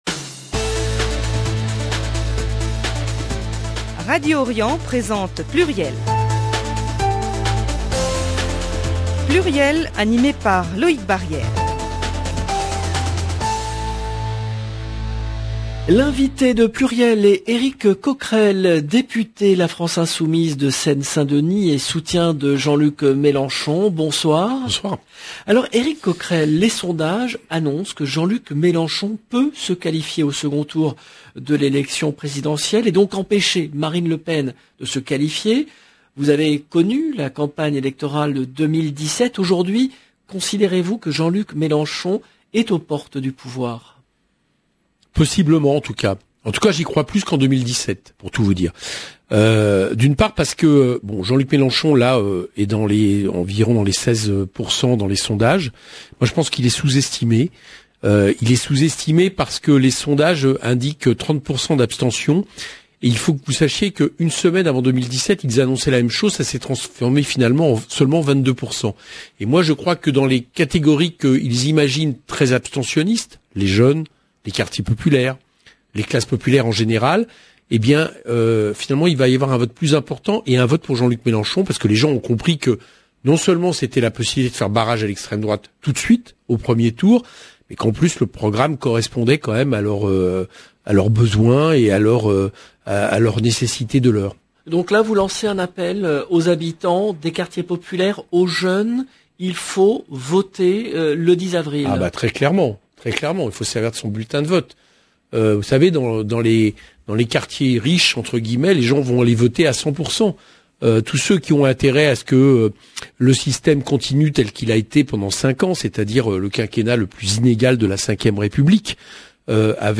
L’invité de PLURIEL est Eric Coquerel , député la France Insoumise de Seine-Saint-Denis et soutien de Jean-Luc Mélenchon